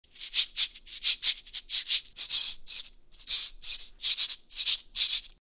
LP Afuche/Cabasa - Mini Plastic (LP234Bk)
The LP Afuche/Cabasa was designed to create rhythmic scraping sounds and patterns. The beads can be manipulated against the textured steel or the instrument can be spun or shaken for varying sounds, the likes of which can be heard in music all over the world. Features plastic flanges and handle.